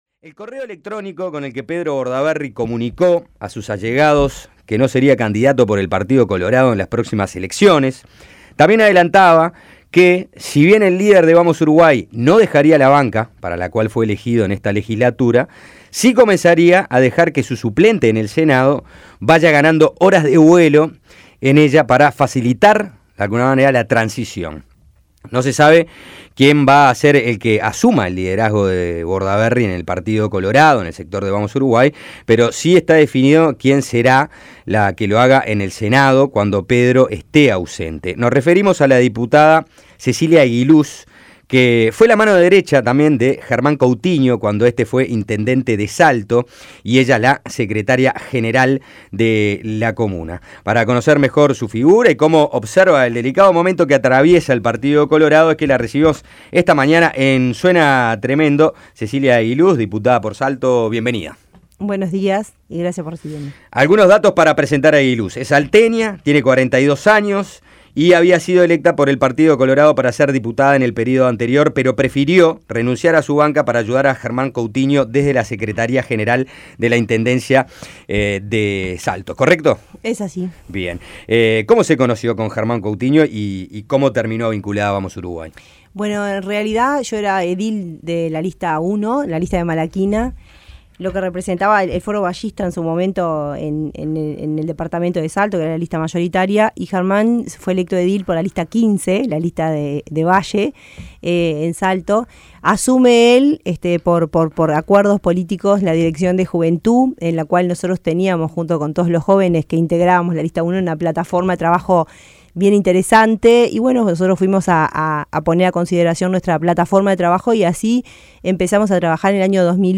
La diputada colorada Cecilia Eguiluz, que suplanta por estos días a Pedro Bordaberry en el Senado, habló en Suena Tremendo sobre el presente del Partido Colorado.
Entrevista en Suena Tremendo